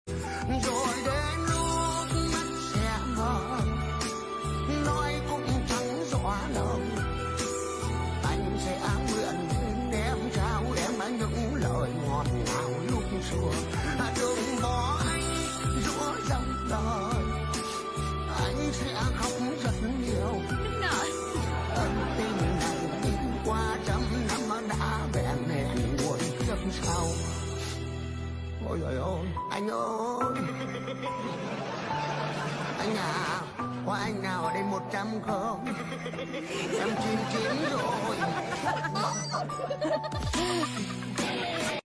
Nhạc chuông 60 lượt xem 15/02/2026